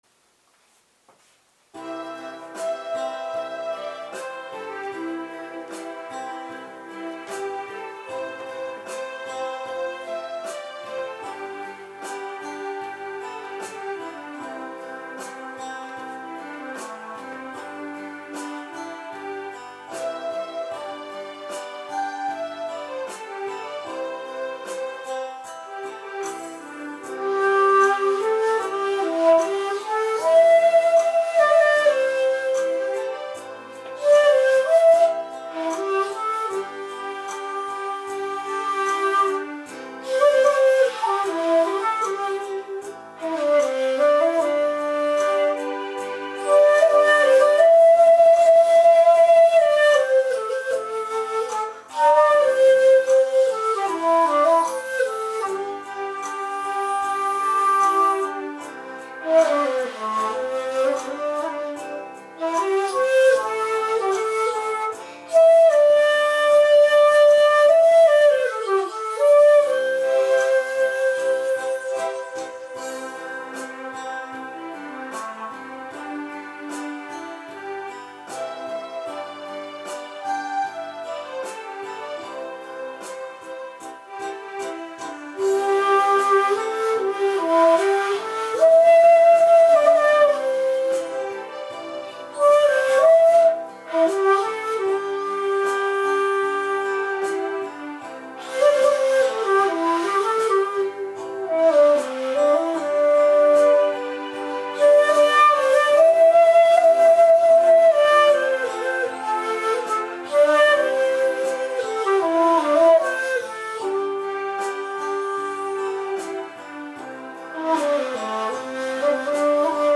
そして二尺四寸管による同曲。
これも地無し延べ竹です。
これも「ほんとうは厳しい」えんそうでした。